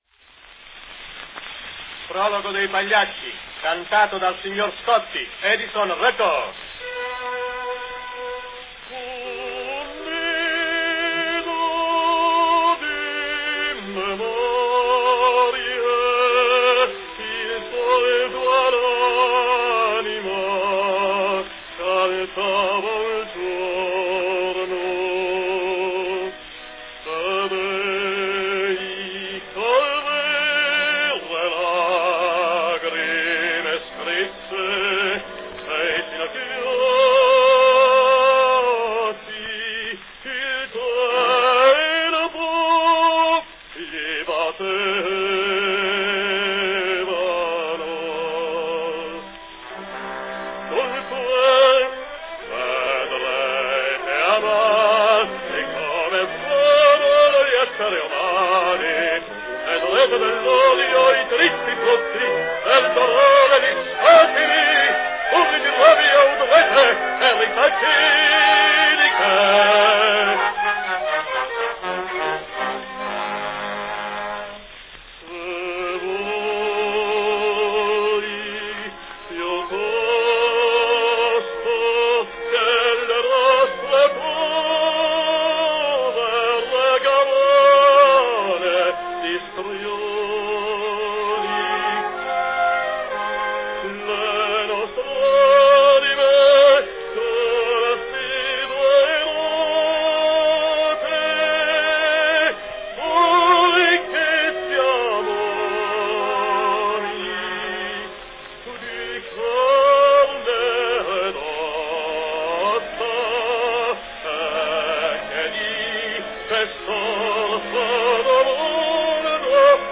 The beautiful prologue to Pagliacci sung on a 1906 Edison Grand Opera cylinder by the great Italian baritone Antonio Scotti.
Category Baritone solo in Italian
Performed by Antonio Scotti
Announcement "Prologo di Pagliacci, cantata dal signor Scotti.  Edison record."
Edison Grand Opera cylinder carton
This selection was apparently recorded (and announced) by Scotti in London in 1905 and later released by Edison in May 1906.